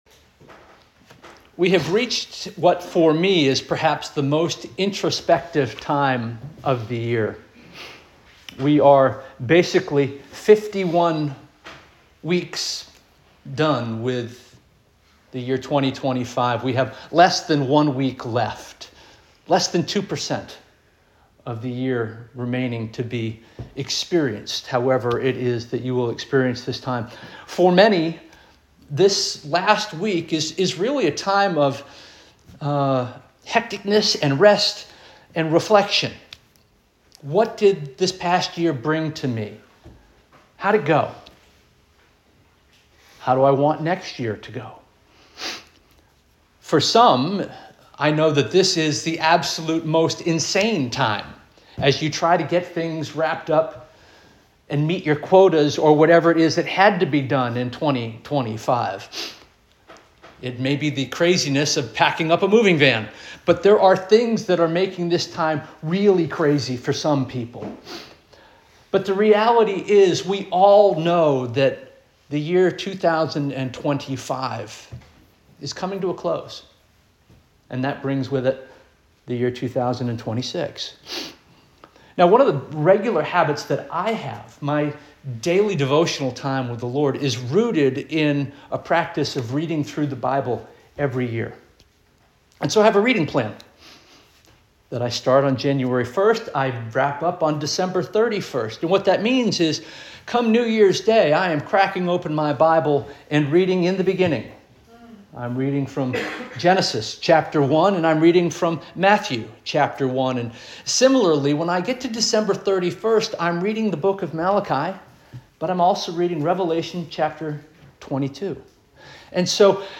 December 28 2025 Sermon - First Union African Baptist Church